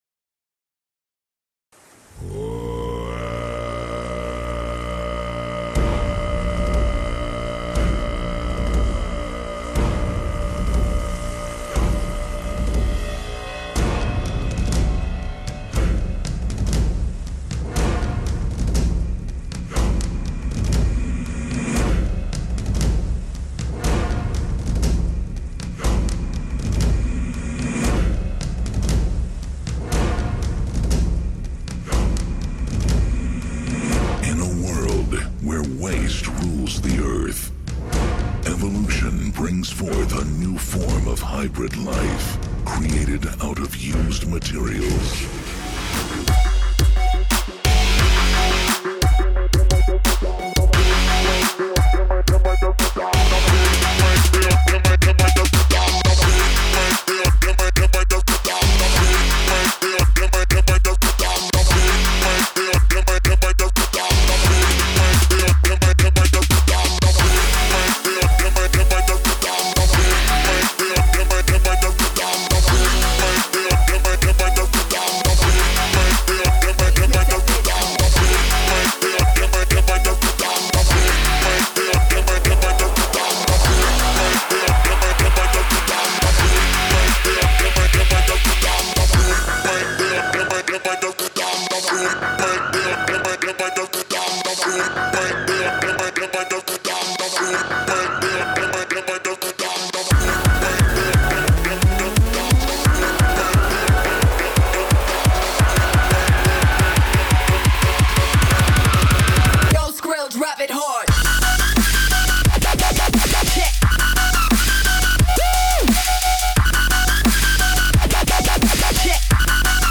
Zde je hudba pro zvukaře.
Ke konci show je falešný konec a kluci se uklánějí. Hudbu je potřeba nechat běžet až do konce.